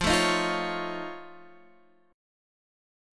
F Augmented 7th